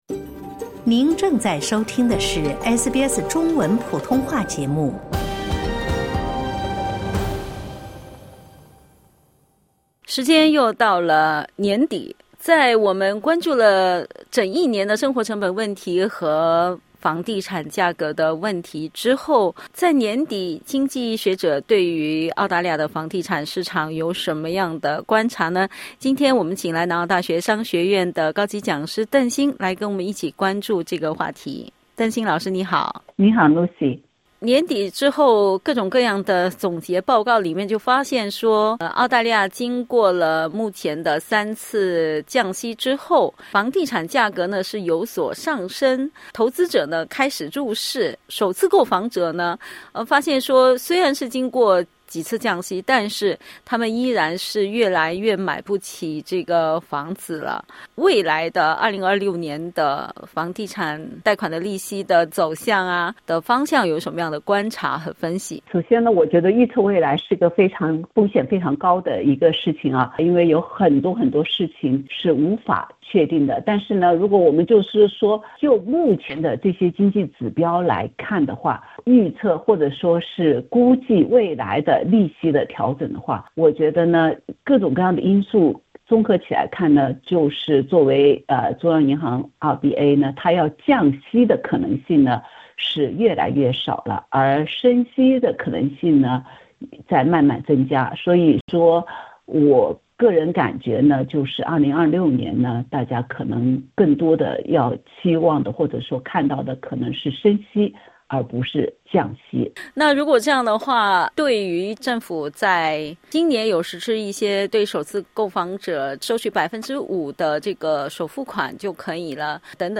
（点击音频收听详细采访） 一份新报告显示，过去五年，住房需求出现了“非同寻常的上涨”，加上住房供应受限，推动了澳大利亚房价和租金的大幅上涨； 住房可负担性则创下过去五年的新低。